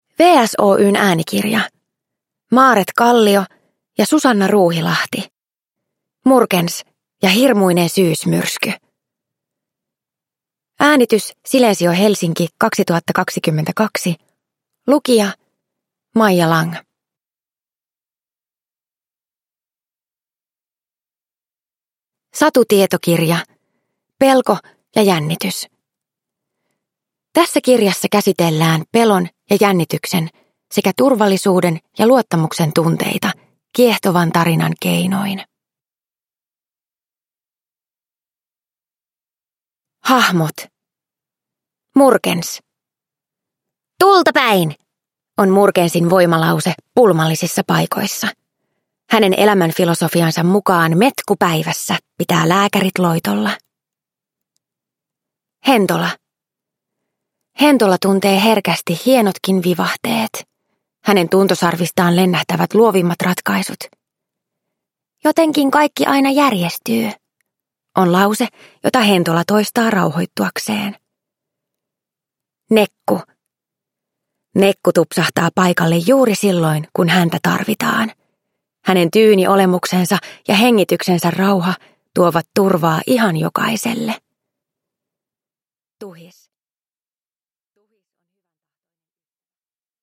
Murkens ja hirmuinen syysmyrsky – Ljudbok – Laddas ner